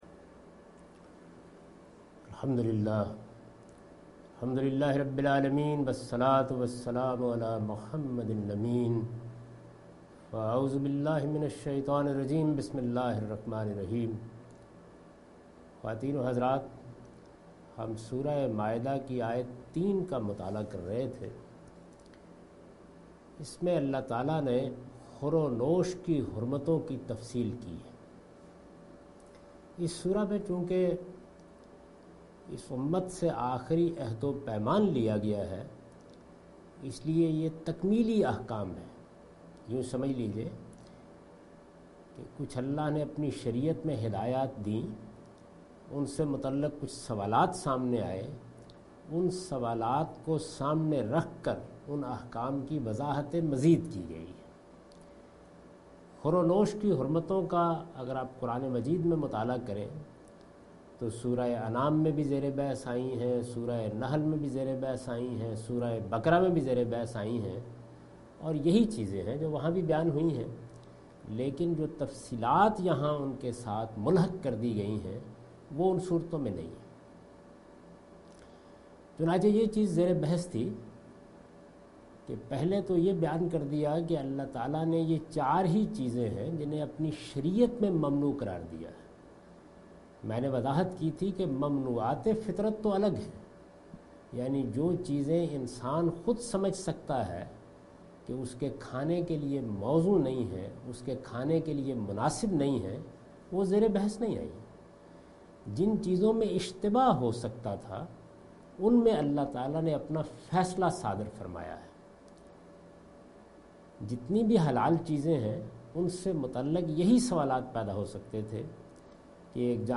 Surah Al-Maidah - A lecture of Tafseer-ul-Quran, Al-Bayan by Javed Ahmad Ghamidi.